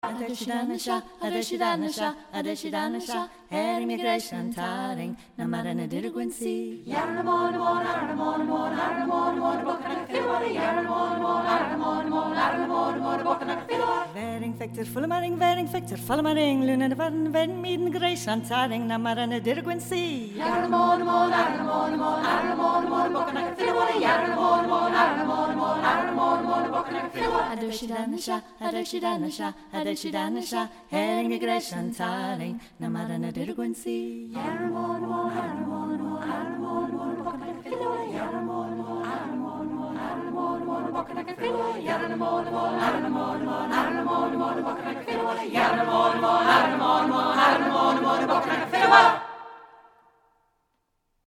calm and riotous, exultant and tranquil
Trad. Celtic